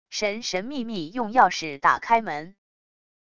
神神秘秘用钥匙打开门wav音频